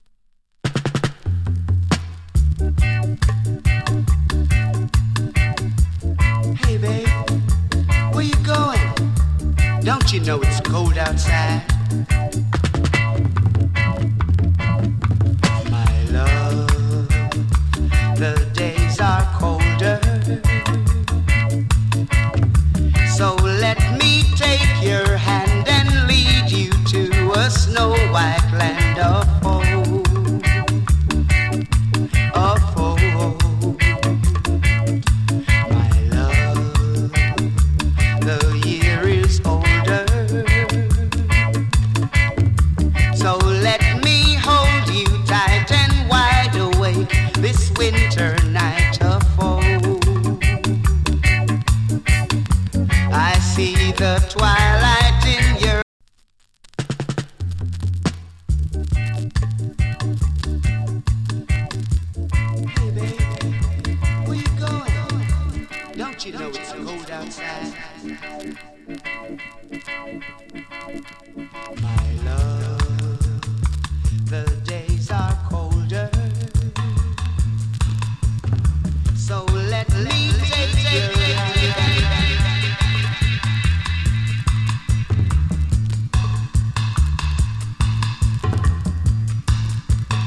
A：VG+ / B：EX- ＊スリキズわずかに有り。チリ、パチノイズわずかに有り。
SWEET VOCAL REGGAE & DUB.